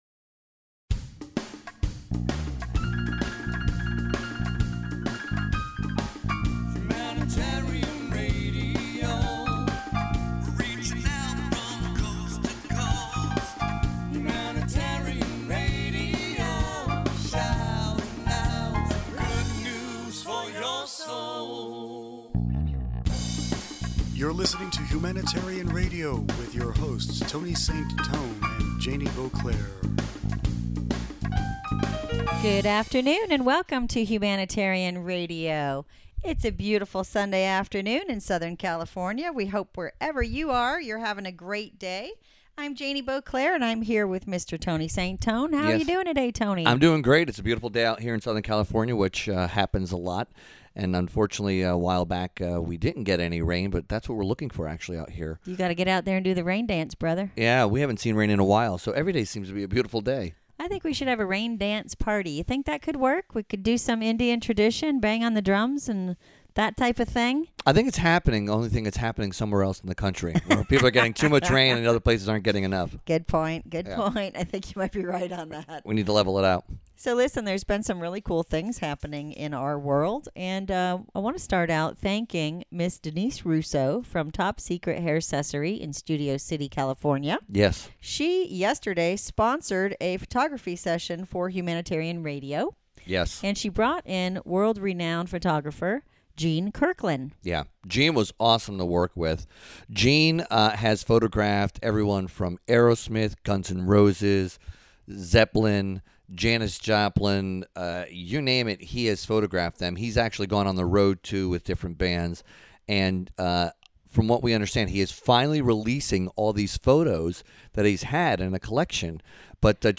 Entertainment HR Interview